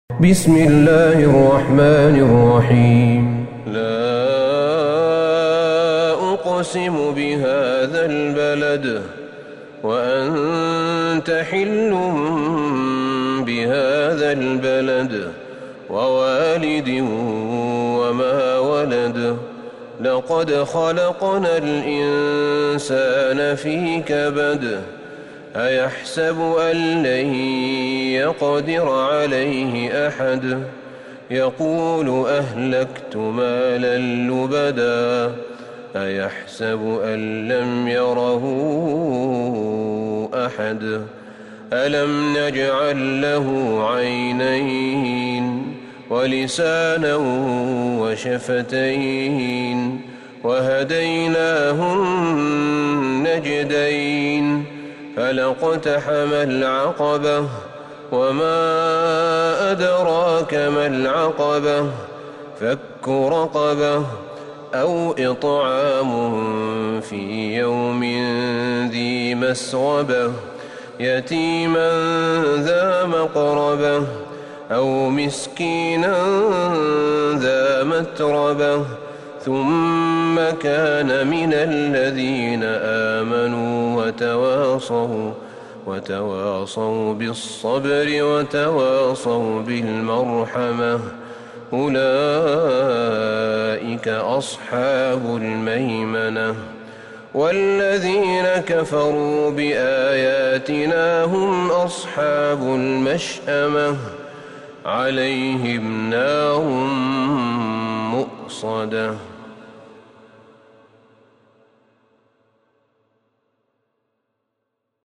سورة البلد Surat Al-Balad > مصحف الشيخ أحمد بن طالب بن حميد من الحرم النبوي > المصحف - تلاوات الحرمين